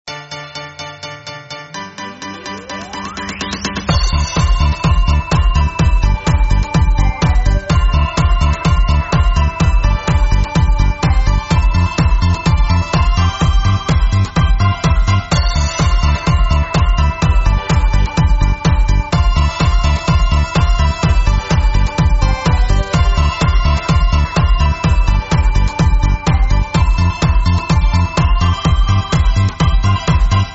Estilo: Pop
Pista musical para jingles estilo «pop»
Calidad de la muestra (48kbps) ⬅Dale click al Play